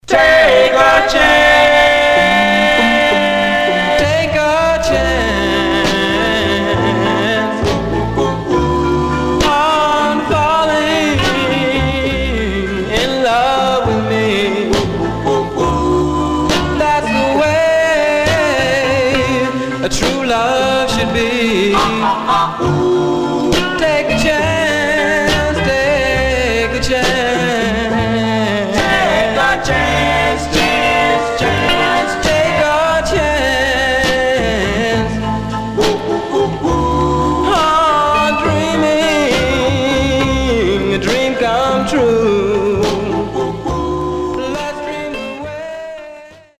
Stereo/mono Mono
Male Black Groups